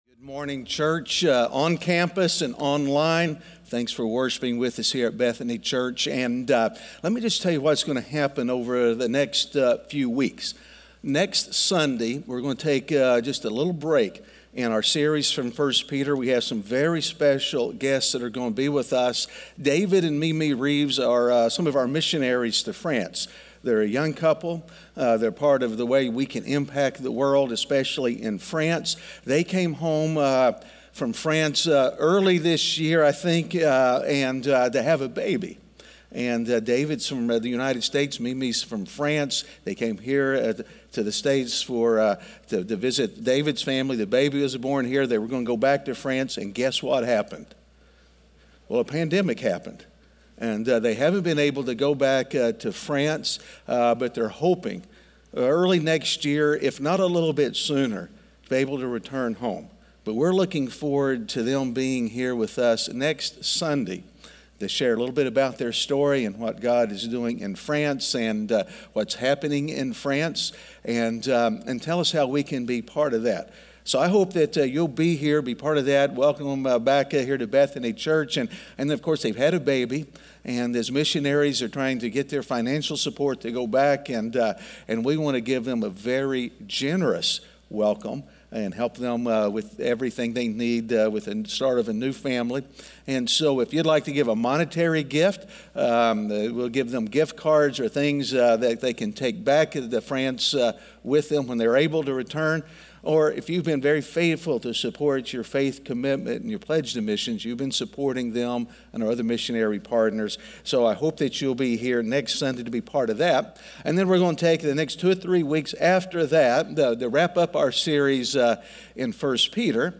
Standing Firm In Shaky Times (Week 12) - Sermon.mp3